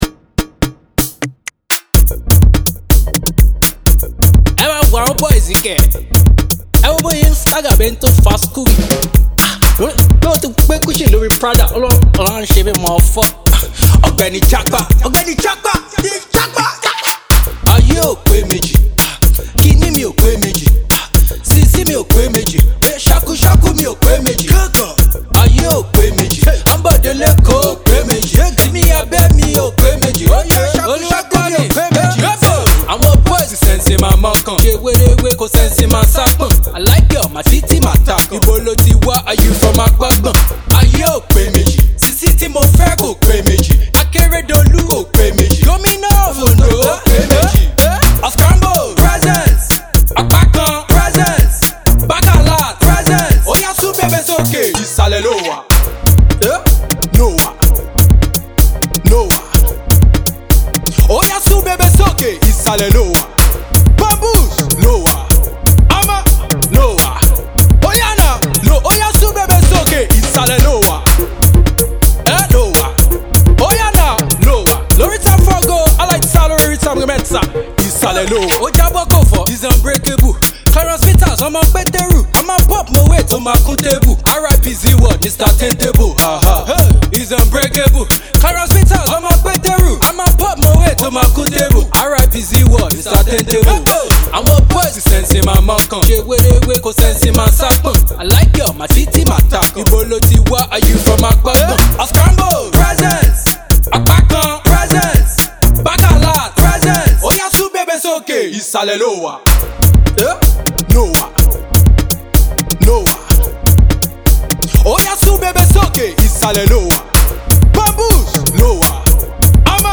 is out again with a club banger